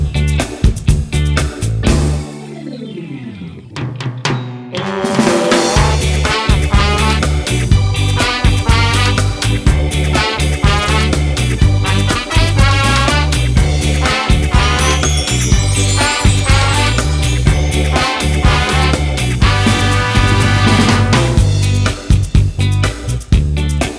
No Backing Vocals Ska 2:55 Buy £1.50